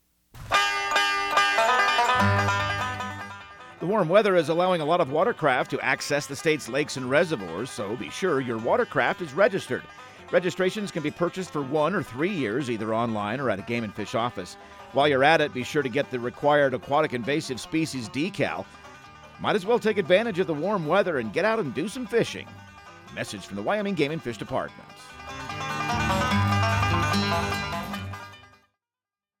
Outdoor Tip/PSA